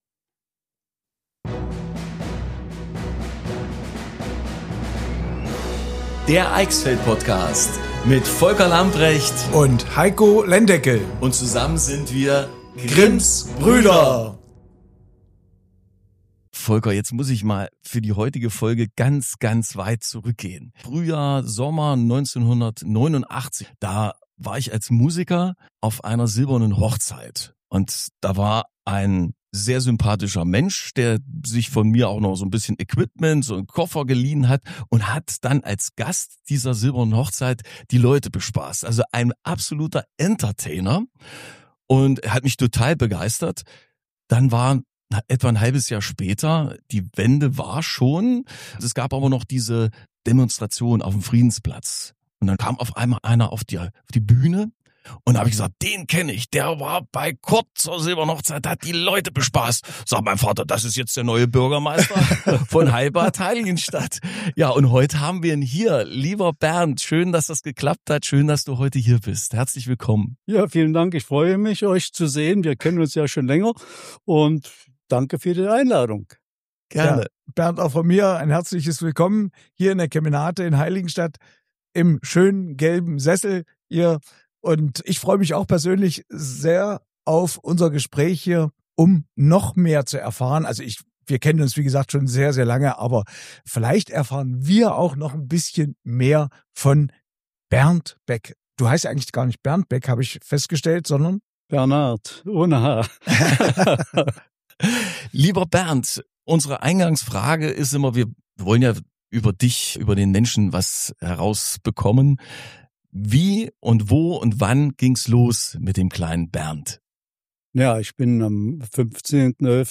Gemeinsam blicken wir zurück auf seine mehr als zwei Jahrzehnte an der Spitze der Stadt. Bernd erzählt offen, warmherzig und mit einer guten Portion Selbstironie von den Highlights seiner Amtszeit – und von den Situationen, bei denen er heute noch schmunzeln muss.
Eine Folge voller Charme, Geschichte und Lacher – genau das, was den Eichsfeld-Podcast ausmacht.